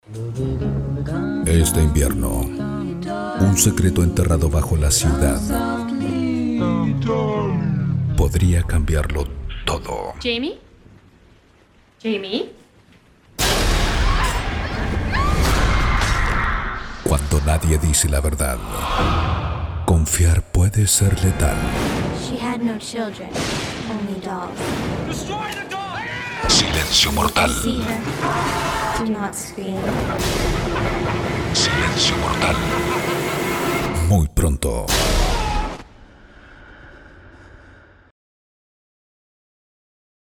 Male
Adult (30-50)
Movie Trailers
Voz Cine
0618DEMO_MOVIE_Trailers.mp3